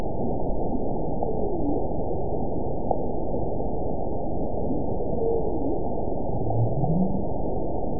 event 922076 date 12/26/24 time 05:21:17 GMT (5 months, 3 weeks ago) score 9.65 location TSS-AB03 detected by nrw target species NRW annotations +NRW Spectrogram: Frequency (kHz) vs. Time (s) audio not available .wav